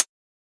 Index of /musicradar/ultimate-hihat-samples/Hits/ElectroHat C
UHH_ElectroHatC_Hit-07.wav